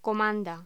Locución: Comanda
voz
Sonidos: Hostelería